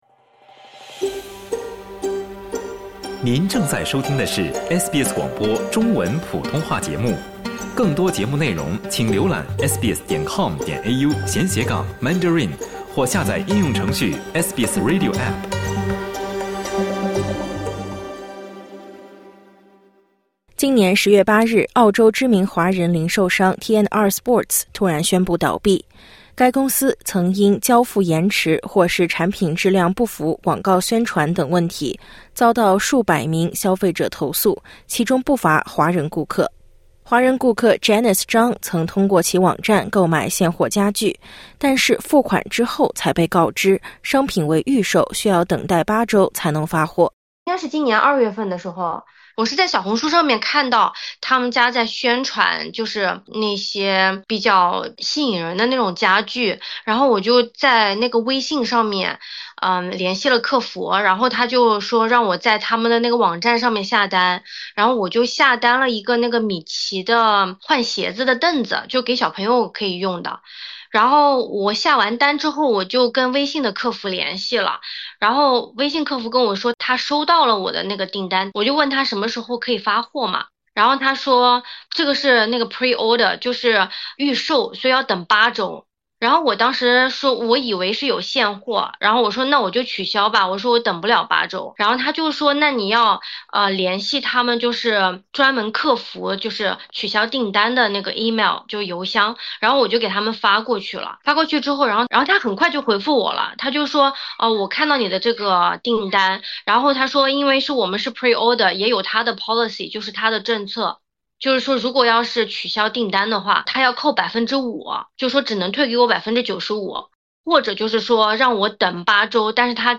今年10月突然倒闭的T&R Sports曾因交付延迟或产品质量不符广告宣传等问题，遭到数百名消费者投诉。点击音频，收听两位华人顾客讲述她们在面对T&R Sports迟迟未能交付货品时的维权经历。